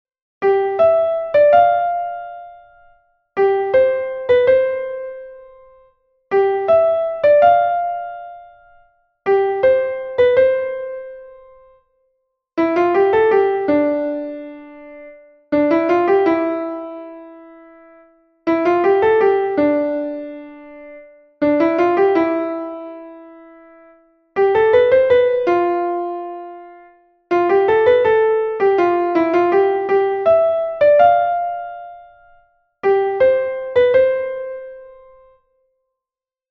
-Ternaria: tres frases.